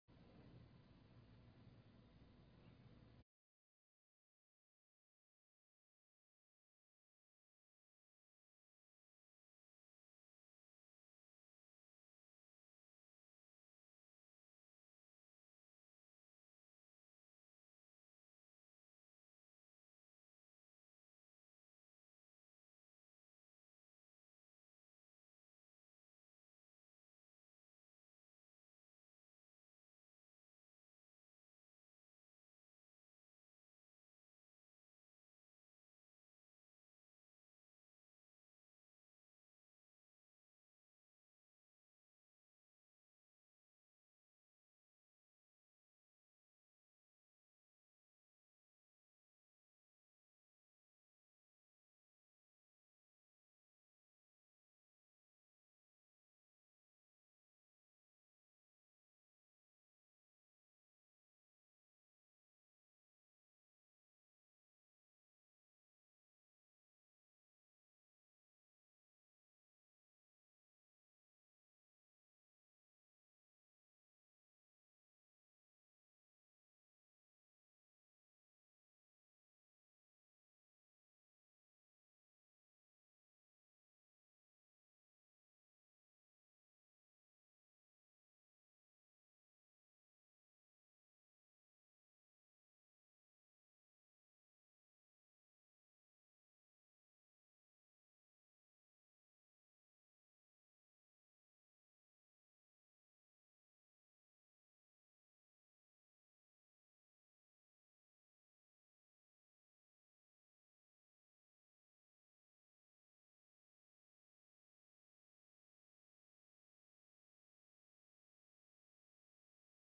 محاضرة التفسير - سورة الزخرف 14